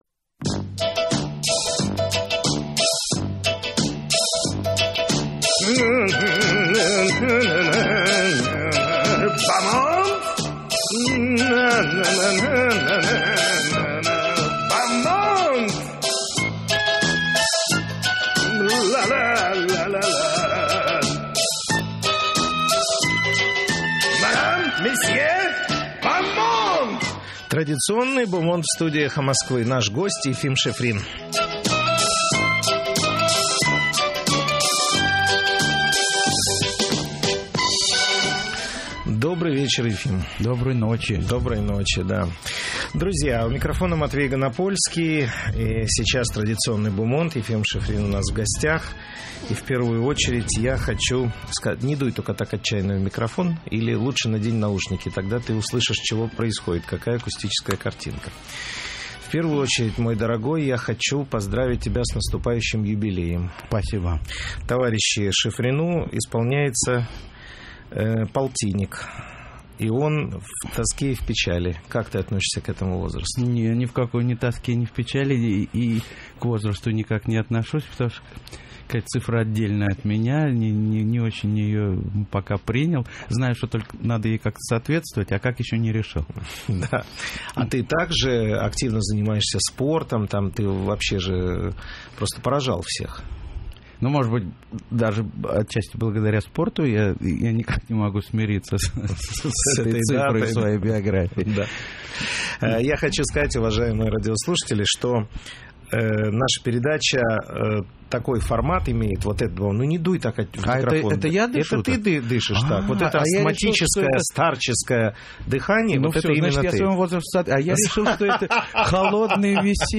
В эфире радиостанции «Эхо Москвы» - Ефим Шифрин, артист эстрады.